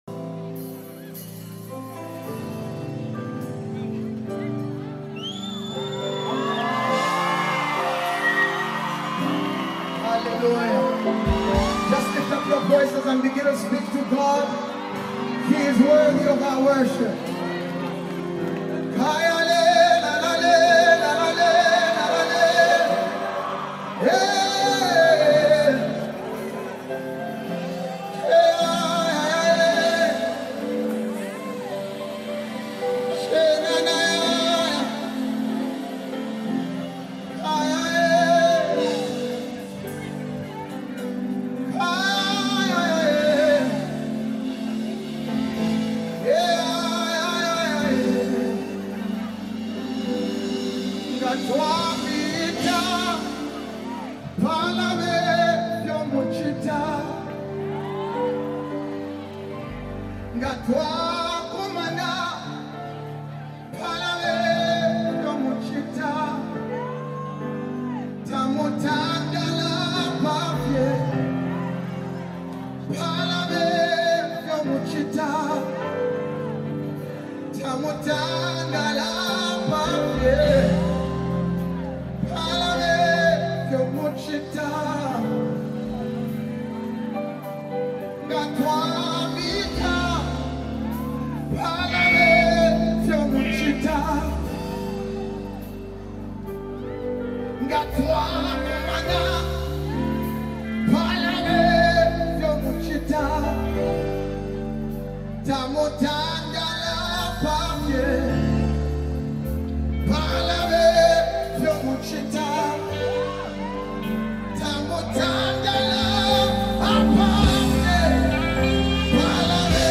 LATEST ZAMBIAN WORSHIP SONG 2025
With its soulful melody and powerful lyrics
anointed voice and passionate delivery